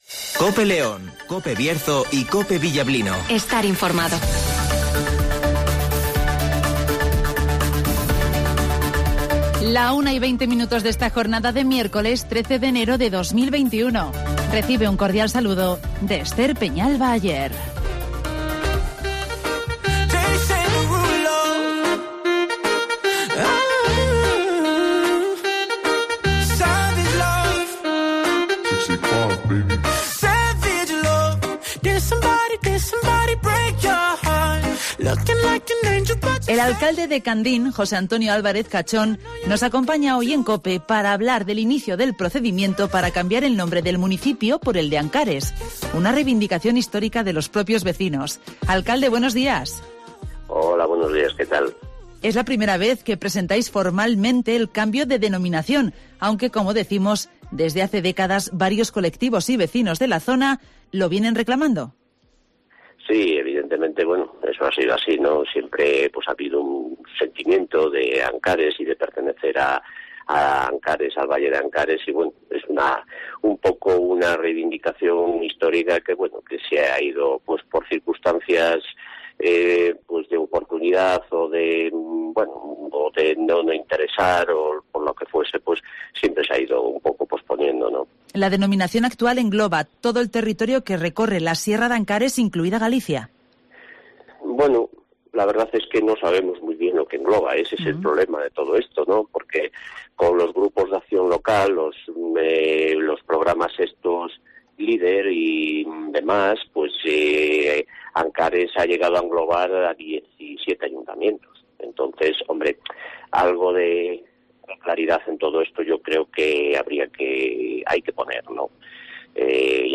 El Ayto de Candín inicia el procedimiento para cambiar su nombre por Ancares (Entrevista a José Antonio Álvarez Cachón, alcalde este municipio berciano)